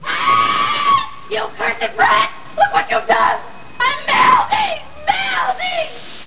1 channel
melting.mp3